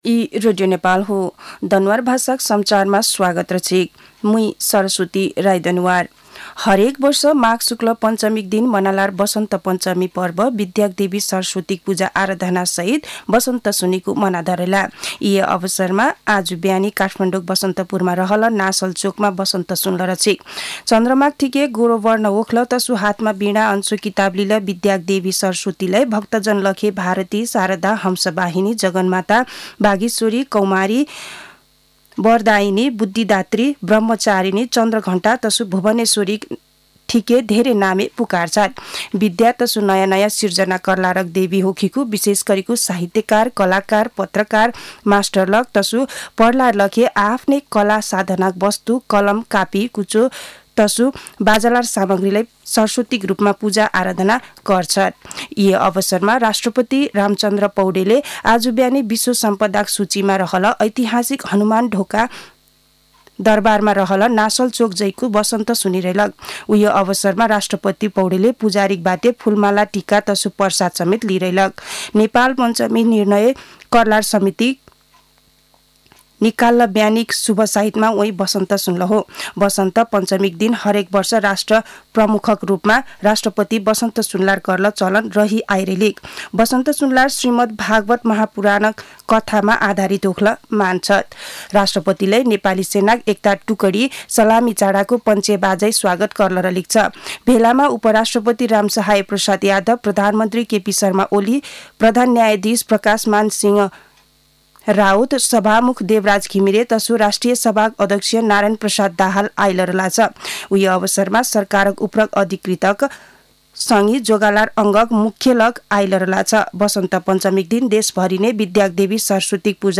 दनुवार भाषामा समाचार : २२ माघ , २०८१
Danuwar-news-.mp3